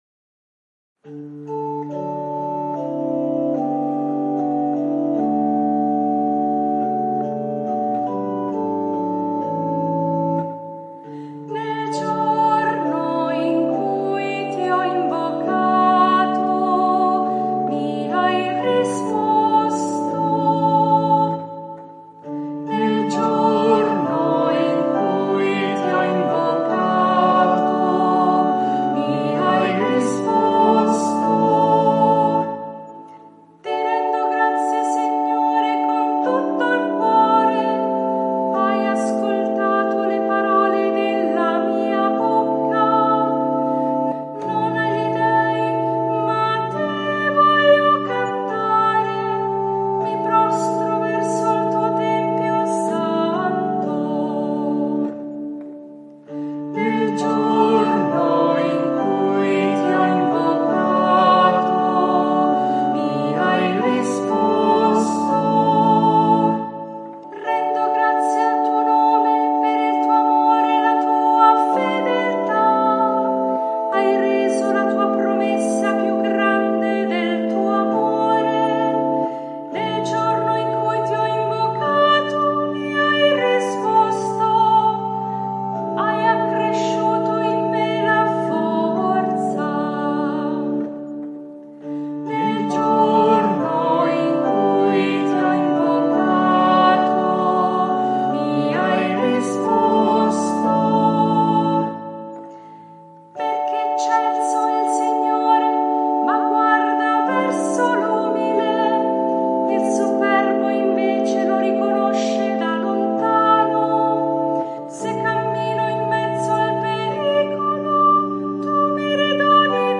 SALMO RESPONSORIALE